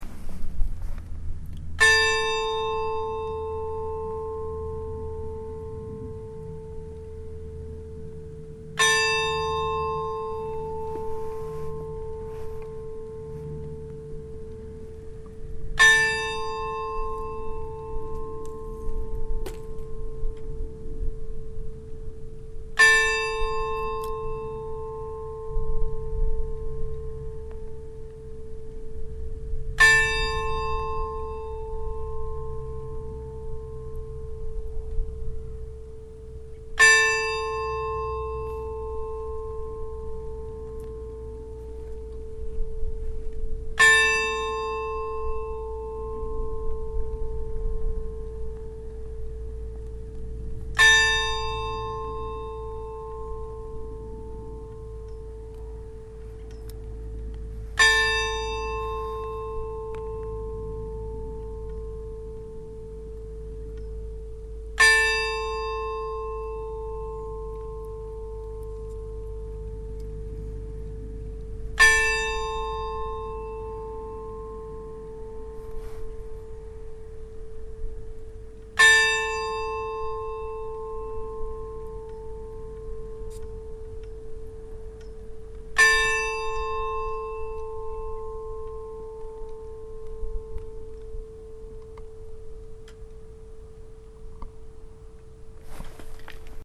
Kirkjuklukkur Reykholtskirkju
Það veldur því að hljómurinn er ekki 100% hreinn úr gömlu klukkunum.
Þá er líkhringing hringd á stærstu klukkuna þegar kista er borin til kirkju að útför lokinni.
Því eru klukkurnar nú notaðar þannig að rafstýrður hamar slær í þær.
reykholtskirkja_likhringing.mp3